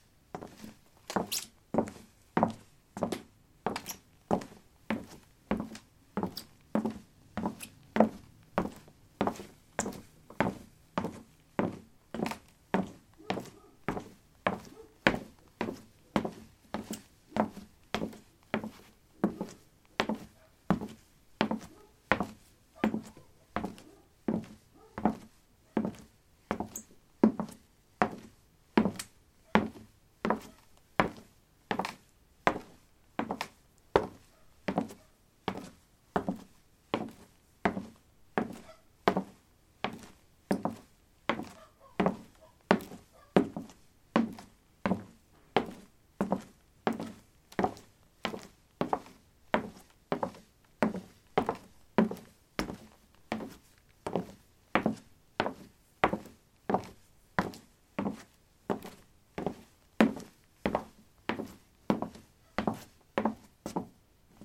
木头的脚步" 木头06A芭蕾舞者的脚步
描述：走在木地板上：芭蕾舞演员。在房子的地下室用ZOOM H2记录：在混凝土地毯上放置的大木桌。使用Audacity进行标准化。
Tag: 脚步 步骤 步骤 走路 脚步 散步